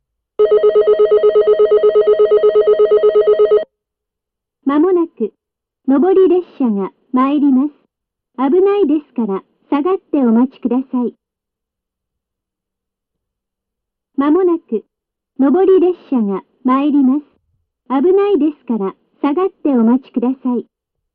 自動放送
上り接近放送
・スピーカー：Panasonicクリアホーン
・接近放送にベルが使われているのは、この辺では山寺駅とここだけかと思います。